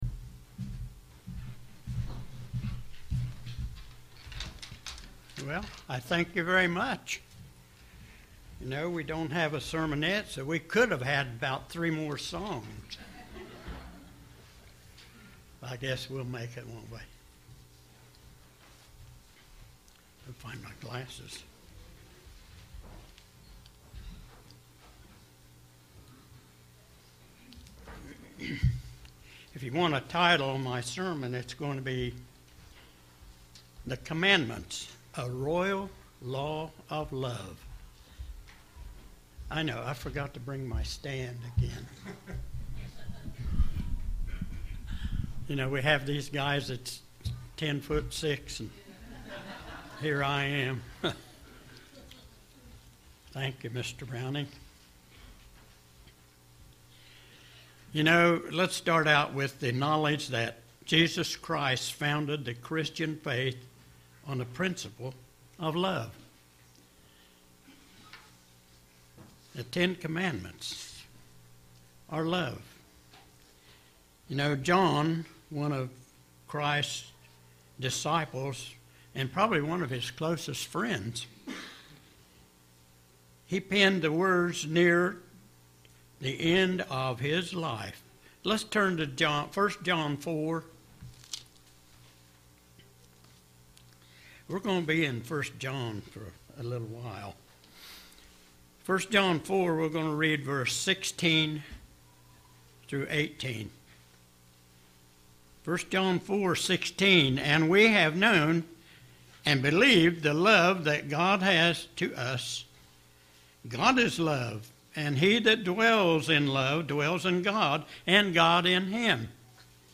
Given in Portsmouth, OH Paintsville, KY